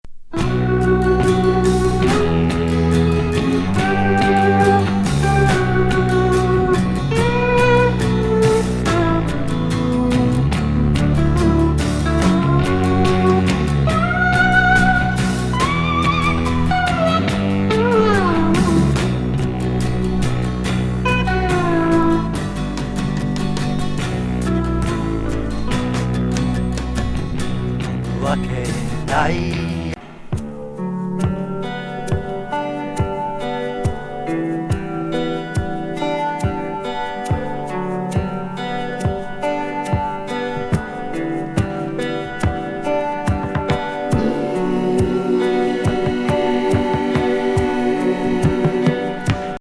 ヘビー・スロウ・フォーキー
トロトロ・エキゾ・サイケデリックな白昼夢アシッド・フォーク